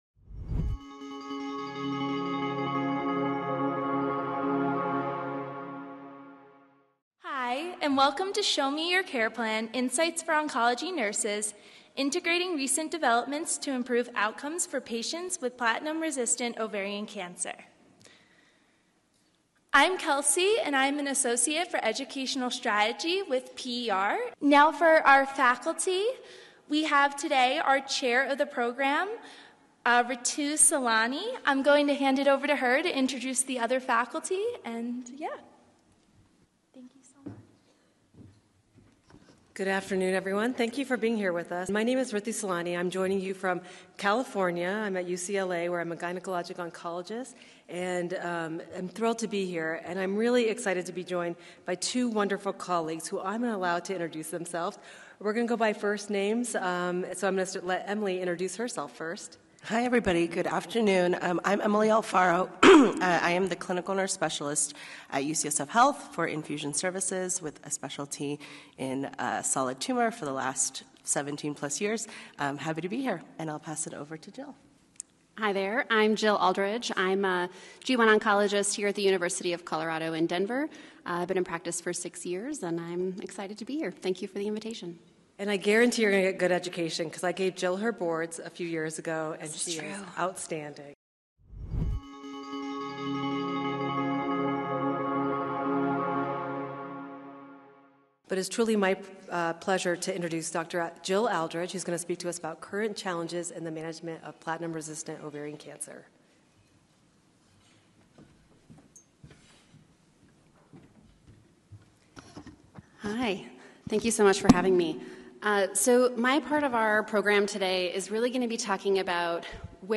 This PER® Show Me Your Care Plan!™ featured podcast brings together two medical oncologists and an oncology nurse to discuss the latest advances in the management of platinum-resistant ovarian cancer (PROC). The expert panel reviews current and emerging therapies, with a special focus on the management of adverse events associated with mirvetuximab soravtansine (MIRV) and other novel agents.